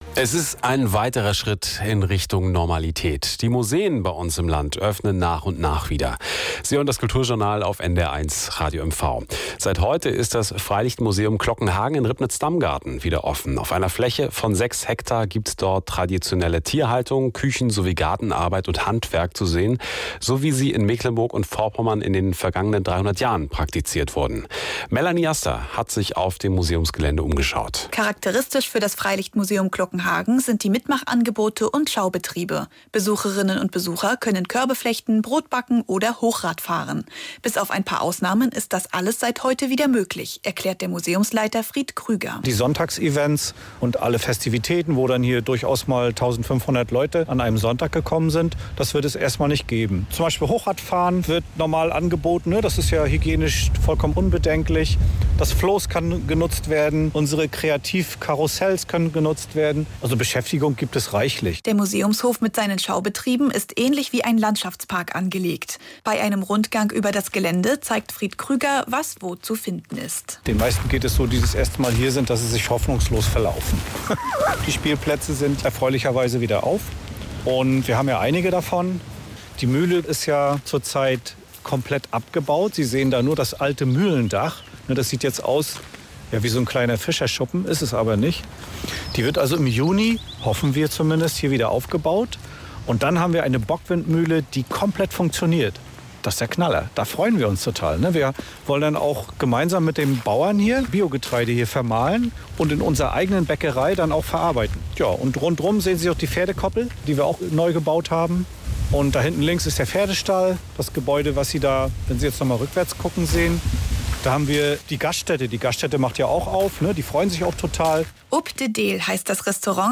NDR Radiobeitrag